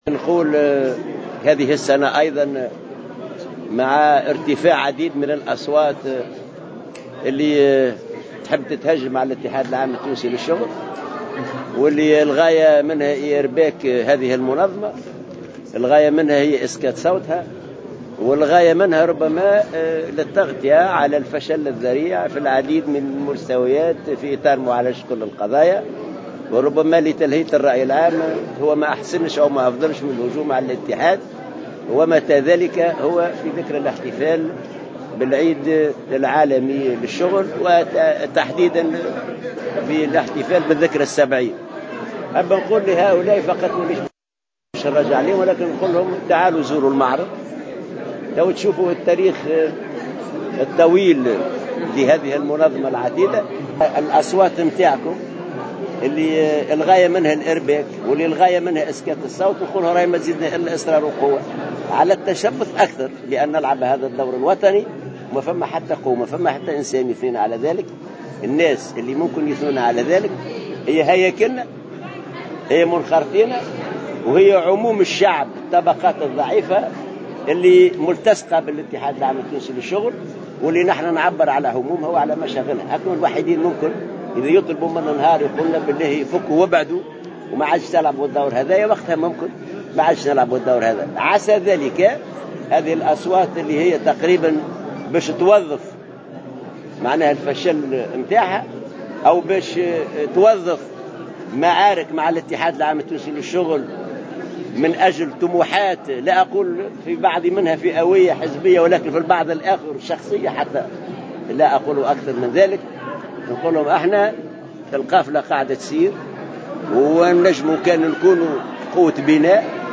وقال رئيس المنظمة الشغيلة على هامش افتتاح معرض وثائقي حول الحركة النقابية احتفالا بعيد الشغل العالمي، إن بعض الأصوات تسعى إلى إرباك الاتحاد وإسكاته من أجل طموحات حزبية وشخصية أحيانا، لتغطية فشلها الذريع في العديد من المستويات وإلهاء الرأي العام، مؤكدا أن هذه المحاولات لن تنجح وأن المنظمة الشغيلة ستواصل دورها كقوة اقتراح وتوازن داخل البلاد.